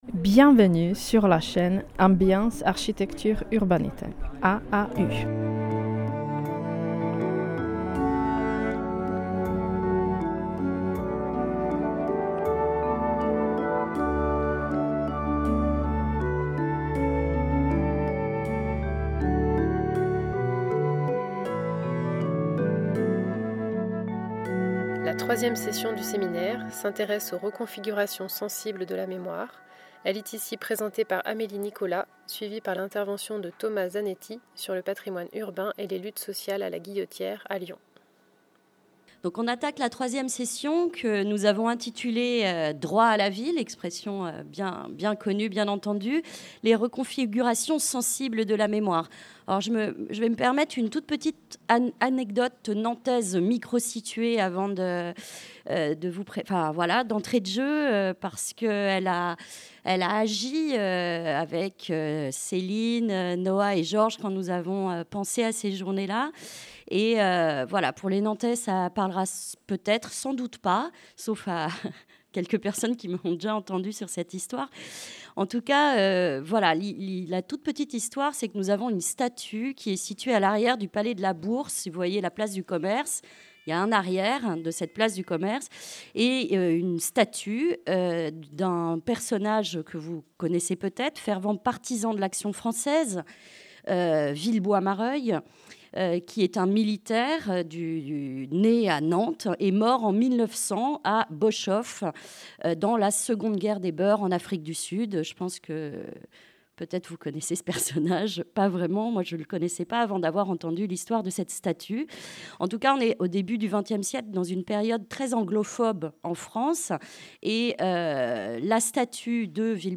La troisième session du séminaire, s'intéresse aux reconfigurations sensibles de la mémoire.